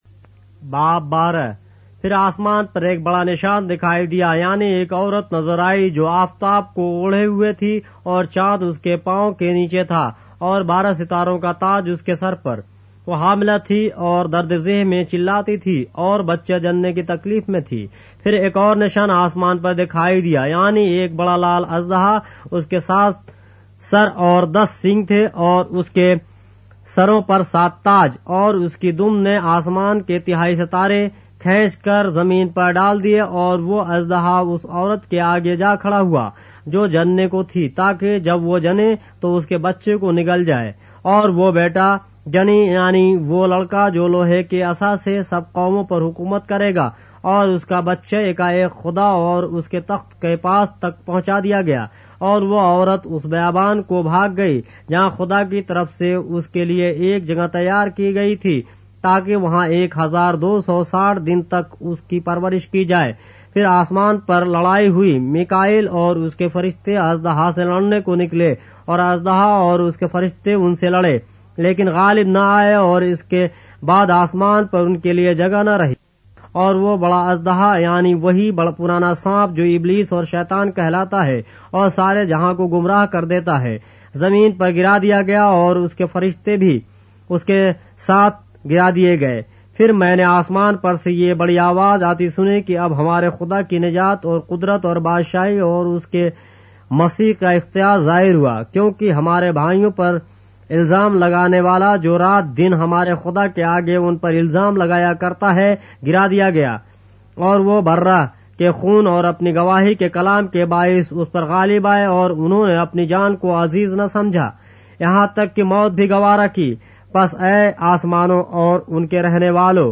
اردو بائبل کے باب - آڈیو روایت کے ساتھ - Revelation, chapter 12 of the Holy Bible in Urdu